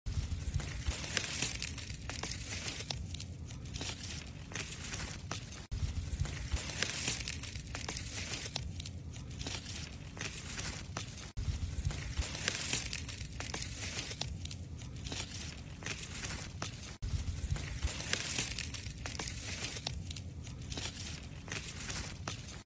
Tiếng Thu hoạch, Nhổ rau củ quả bằng tay…
Tiếng dùng kéo Thu hoạch rau, củ, quả và ném vào sọt Tiếng dùng dao, lưỡi liềm, tay… thu hoạch rau củ quả sột soạt
Thể loại: Tiếng động
Những tiếng động nhẹ nhàng, tiếng lá cây xào xạc, tiếng rễ cây bị rút lên khỏi đất hay tiếng quả rơi vào rổ… mang đến cảm giác gần gũi, mộc mạc.
tieng-thu-hoach-nho-rau-cu-qua-bang-tay-www_tiengdong_com.mp3